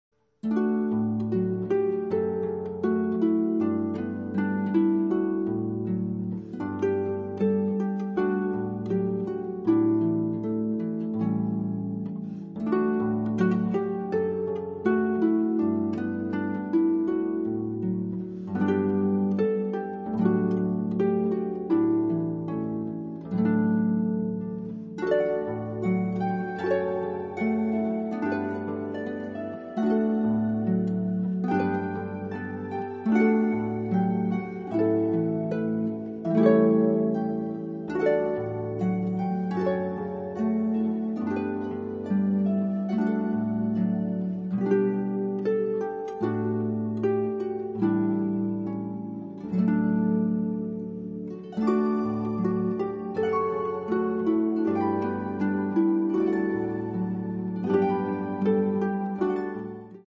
Genre: Hymns, Praise and Gospel